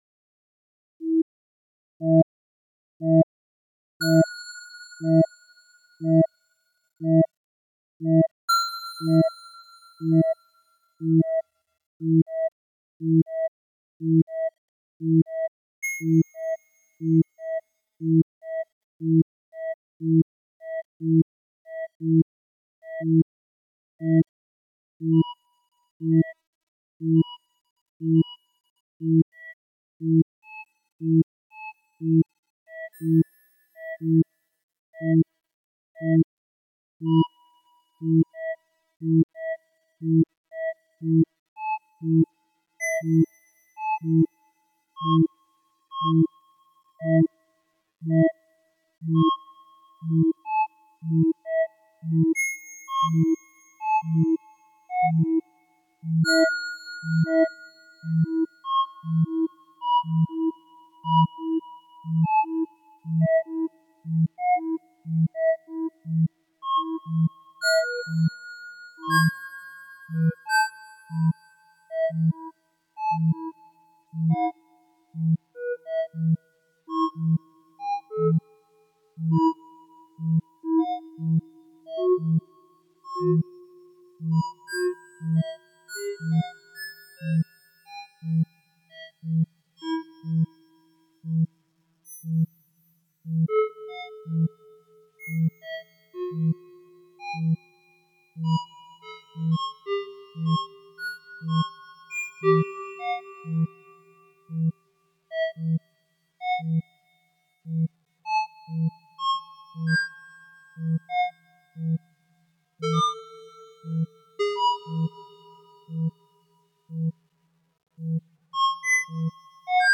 Four Functions electroacoustic (2010)
Written as part of a survey of computer music software, the single sound of this piece is divided into 4 discrete octaves and is each governed by a different mathematical function.